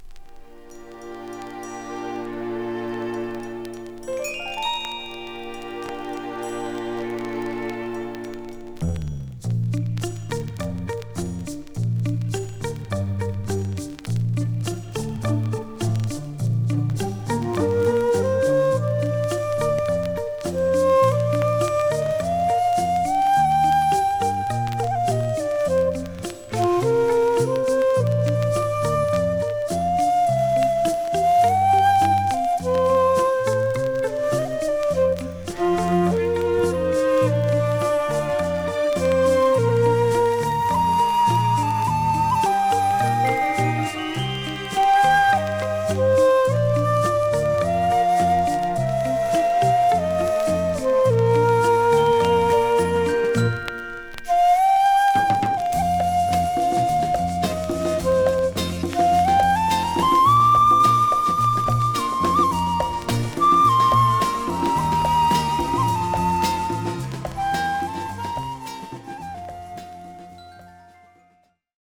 ドボルザーク新世界より「家路」の尺八イージーリスニング・ファンクカバー◎ 奇妙なジャパニーズ・モンド・レコード中南米編。